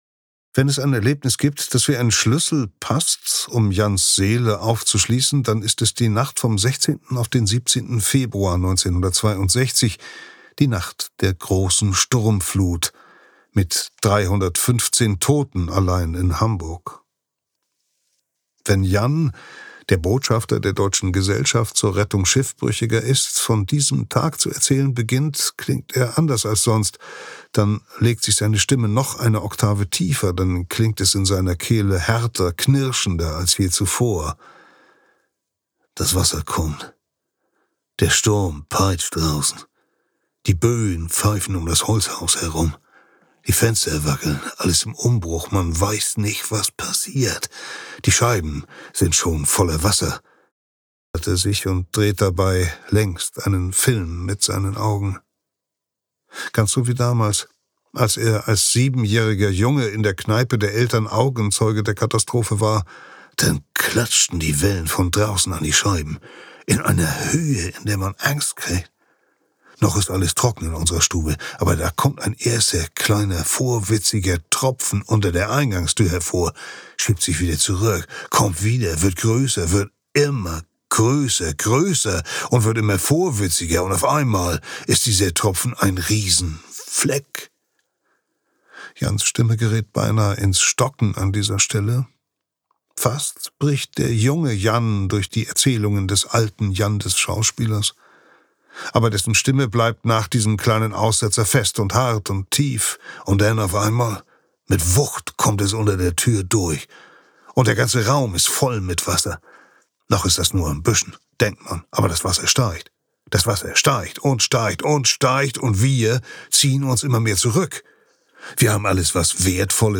Junge männliche Sprecher Stimmen
Eine jugendliche, hohe, frische Männerstimme für junge, vorwiegend männliche Zielgruppen.